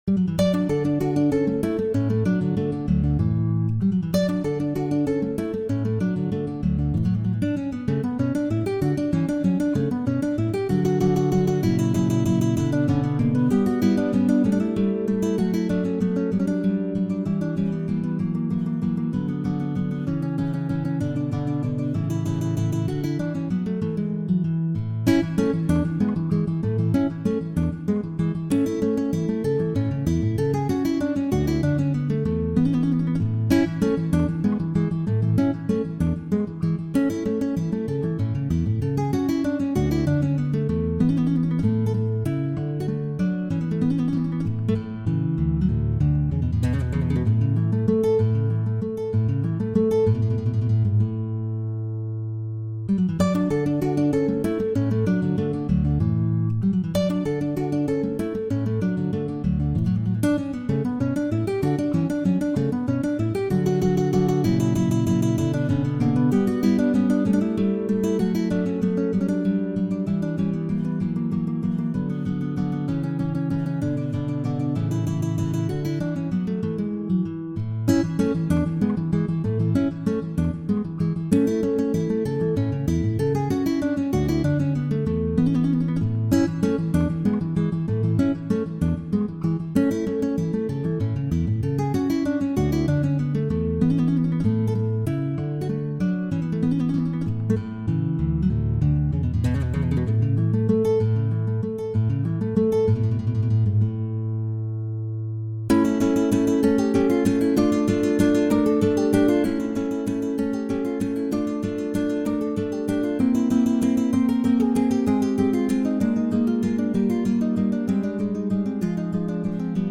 Sonate-n°84-en-re-majeur.mp3